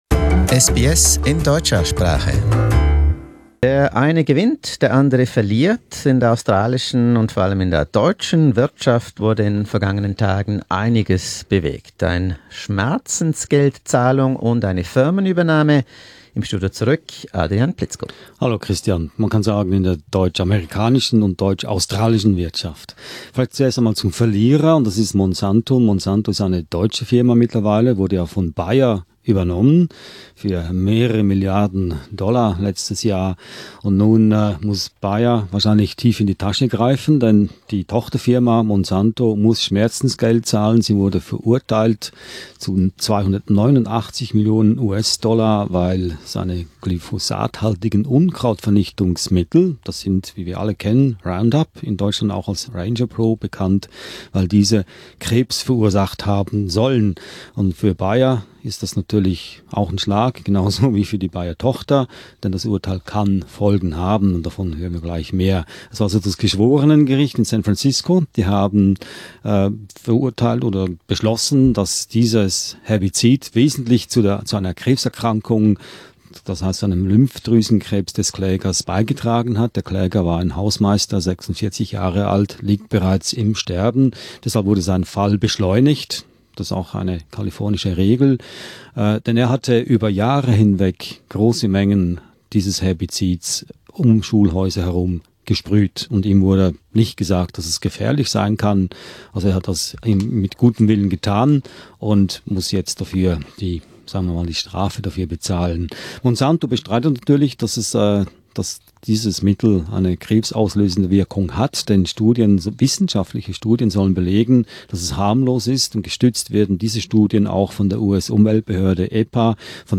In this brief economy news we look at the world's first Roundup cancer trial and Continental's decision to buy one of Australia's big auto repair and tyre workshop.